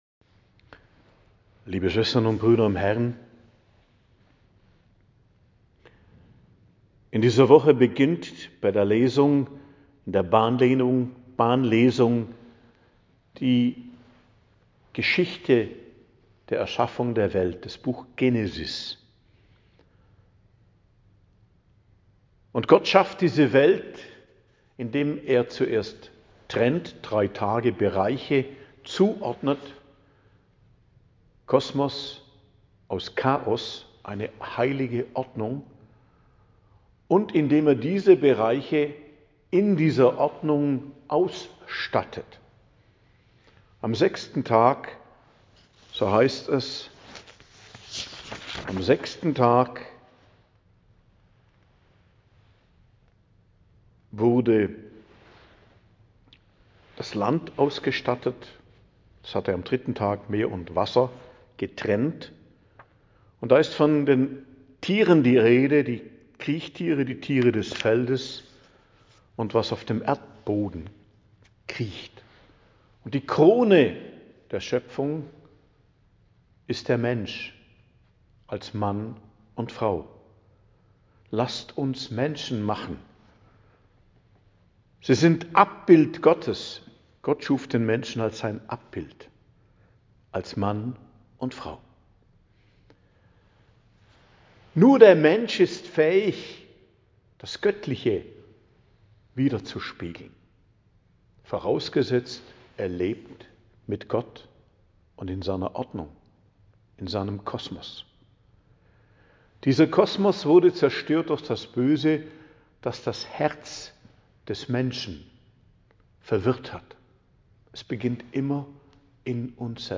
Predigt am Dienstag der 5. Woche i.J. 11.02.2025 ~ Geistliches Zentrum Kloster Heiligkreuztal Podcast